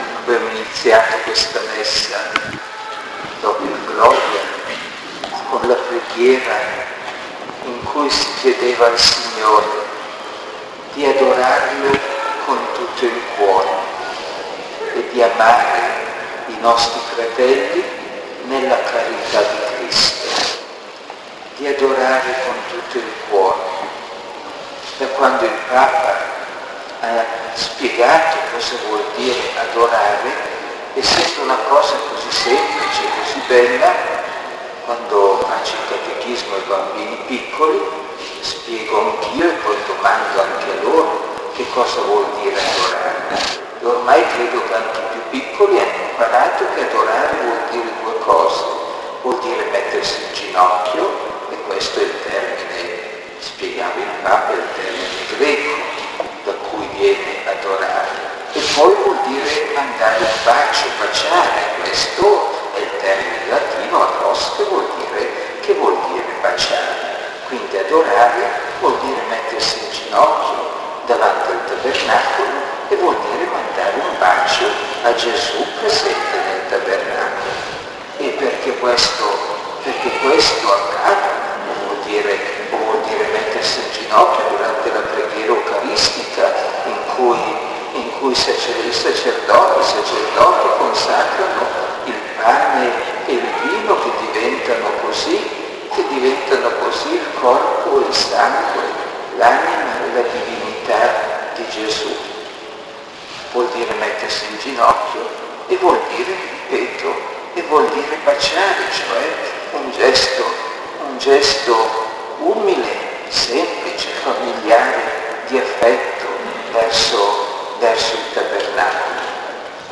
OMELIA (incompleta)